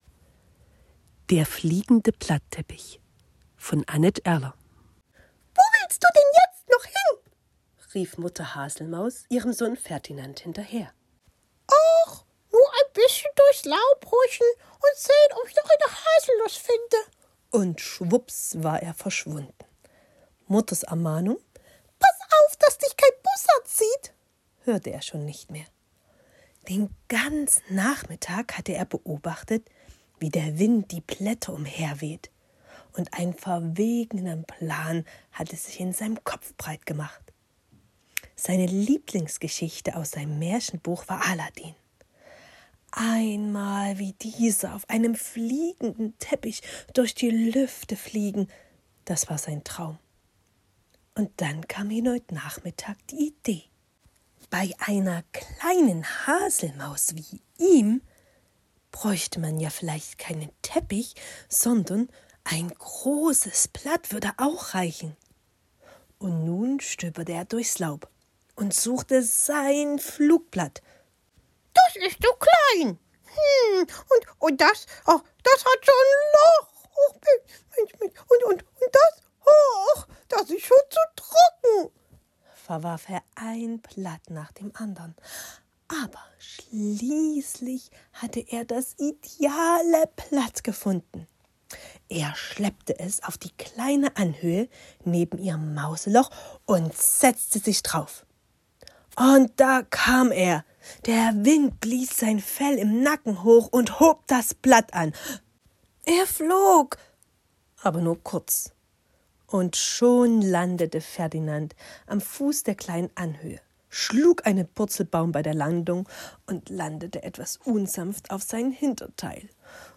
Hörbuchgeschichten